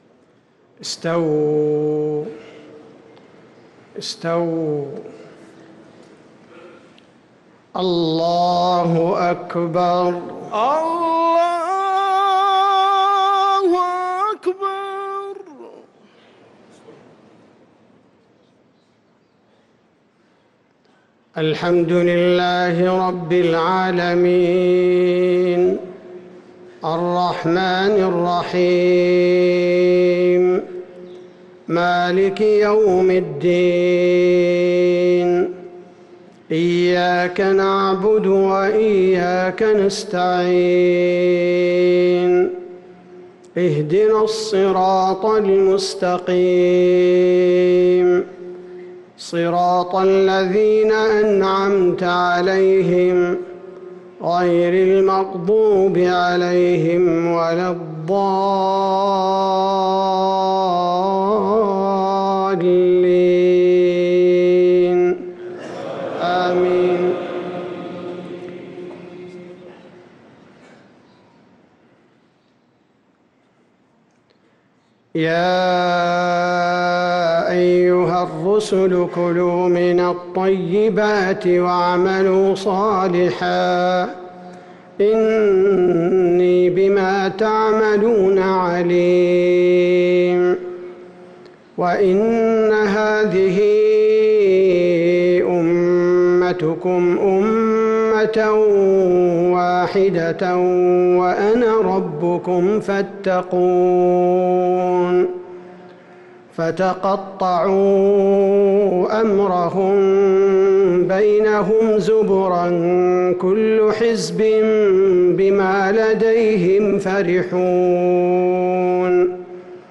صلاة المغرب للقارئ عبدالباري الثبيتي 12 ربيع الأول 1445 هـ
تِلَاوَات الْحَرَمَيْن .